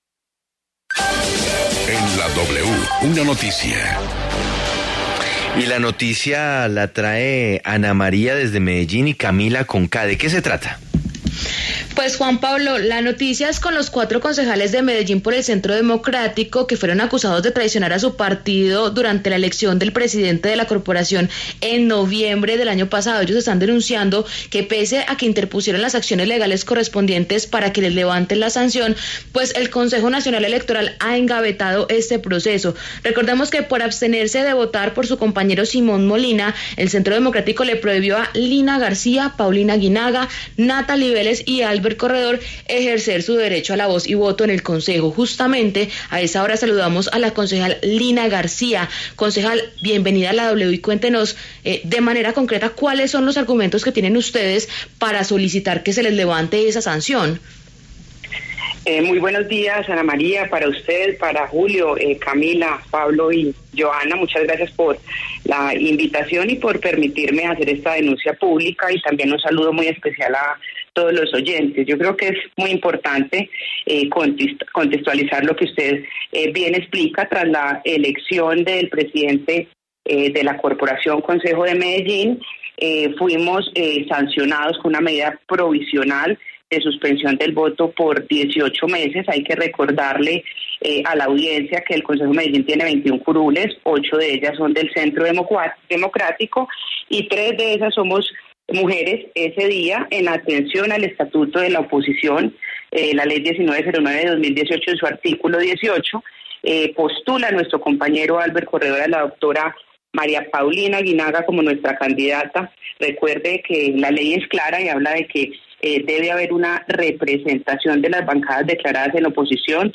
En entrevista con W Radio, la concejal García explicó que aún no se ha resuelto la recusación en contra del magistrado Renato Contreras, quien lleva el caso.